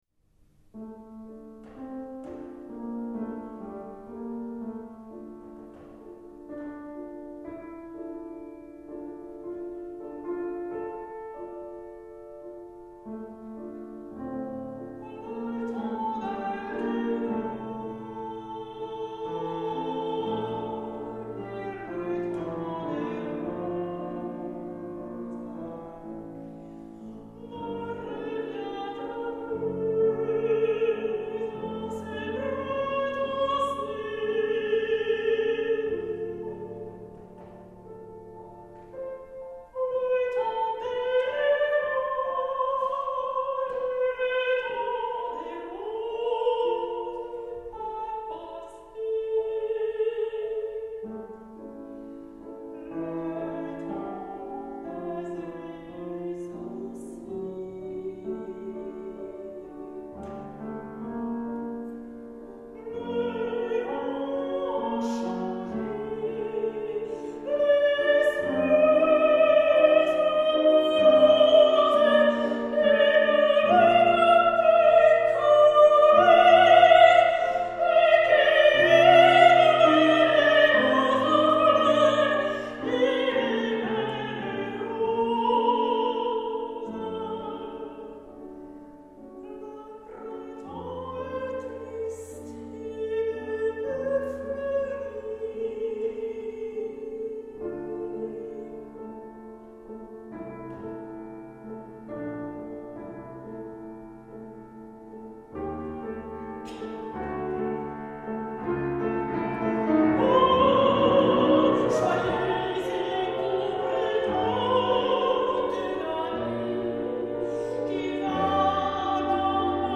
мецо сопрано
пиано